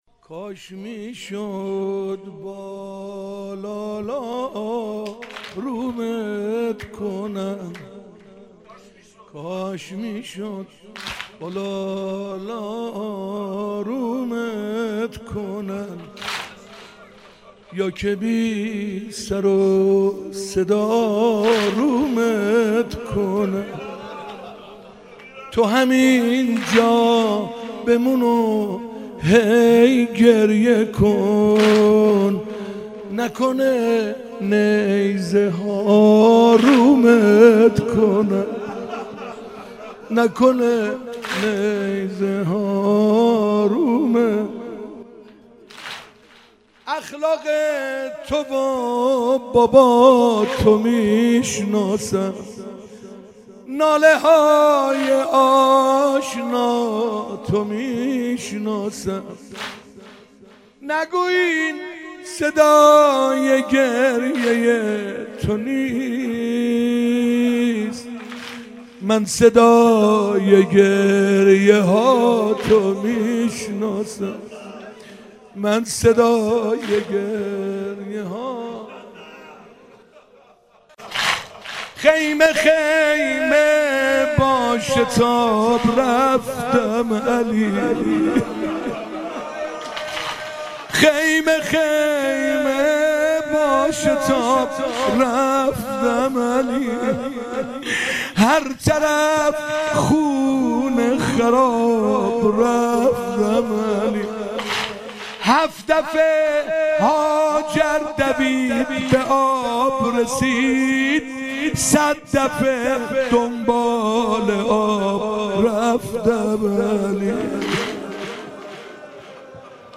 شب هفتم محرم 96
واحد - کاش میشد با لالا آرومت کنم